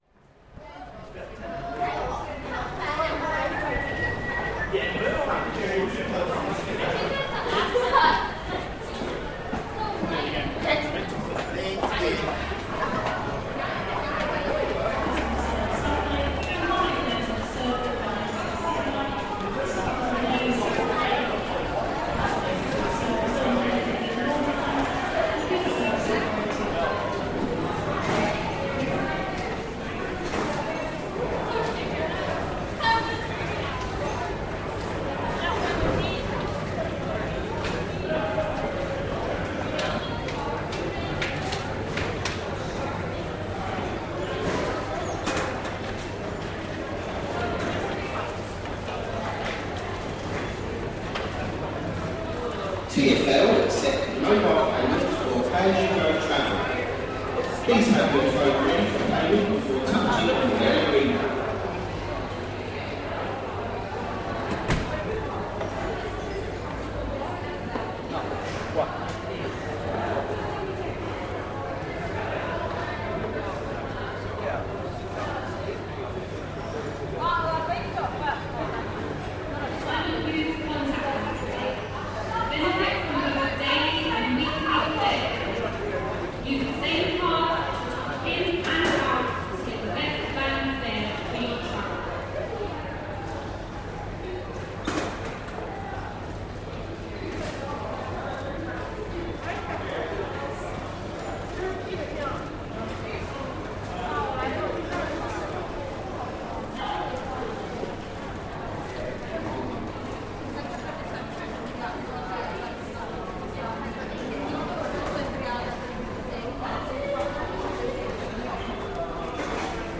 Field recording from the London Underground by Cities and Memory.